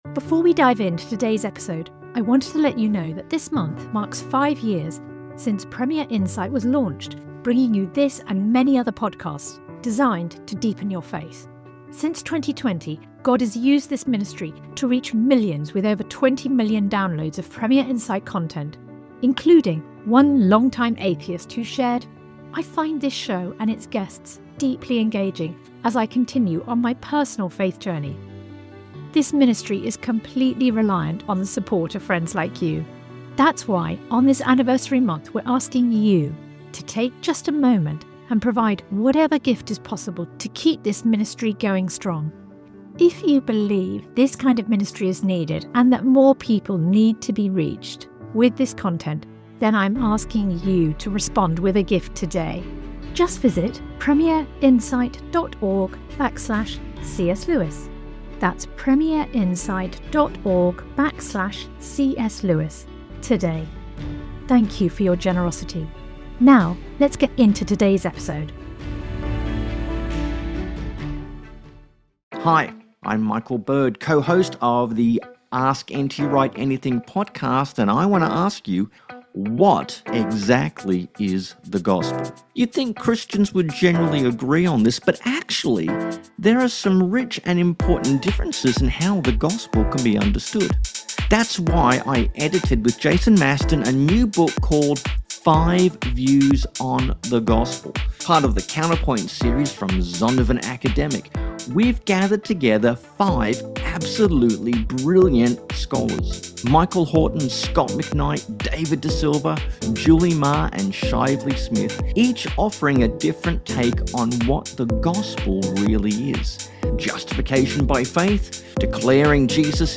In today's episode, we get to hear his paper, which was titled Fern-Seed, Elephants, Bultmann, and Lewis - Varied Expertise in Critical Perspective, live from the 2024 Undiscovered CS Lewis conf…